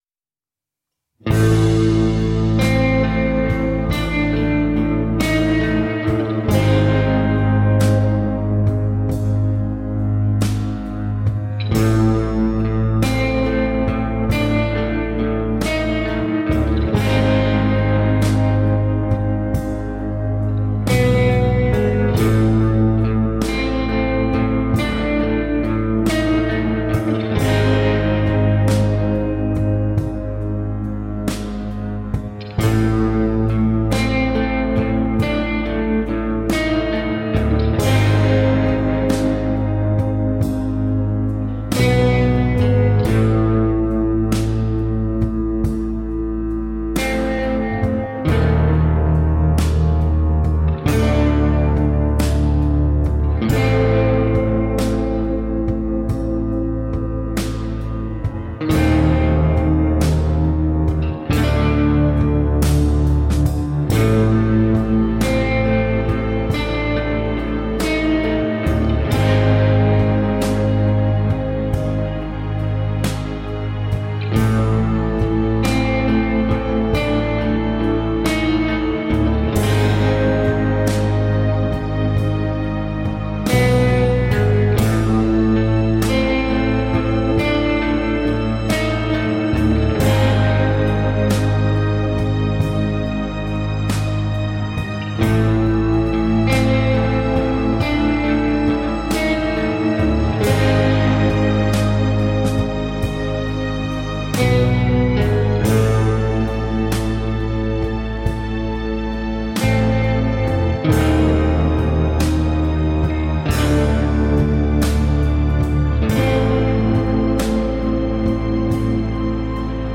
Organic, atmospheric acoustic-guitar textures.